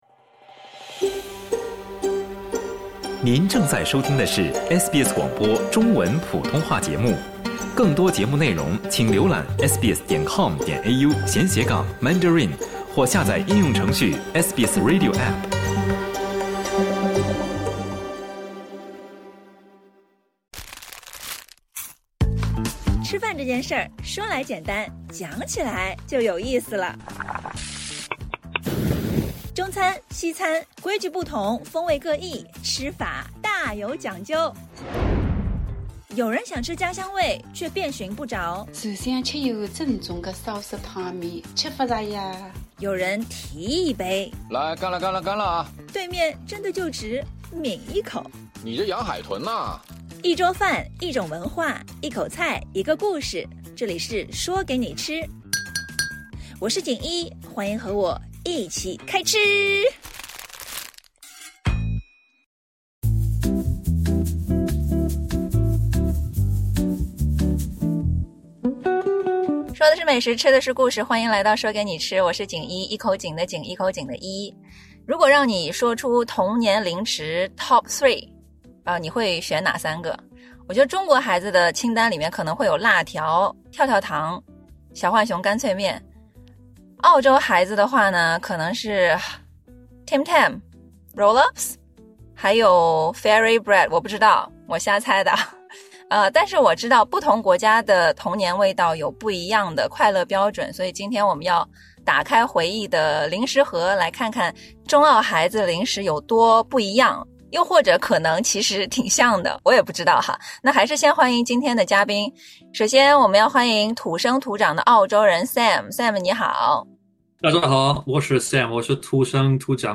本期话题：辣条遇上Vegemite 畅谈澳中童年味道 本期嘉宾（两位老朋友）